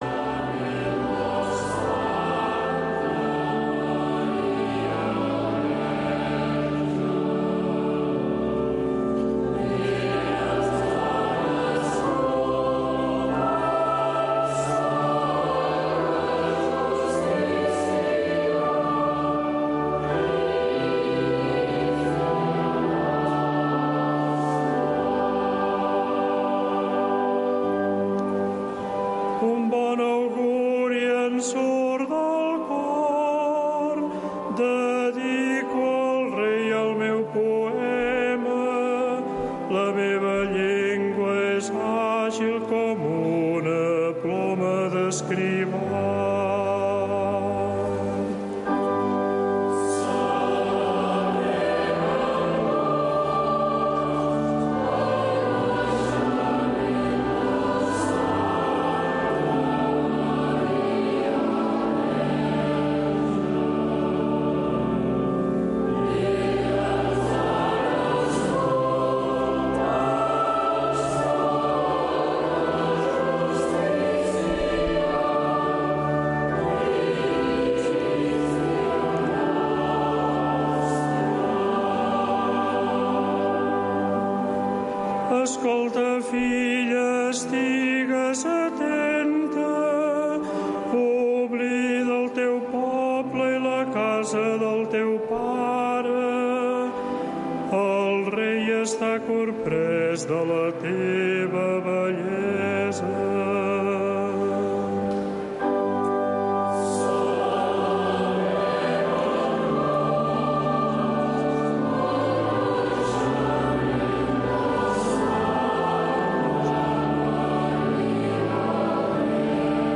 Retransmissió en directe de la missa conventual des de la basílica de Santa Maria de Montserrat.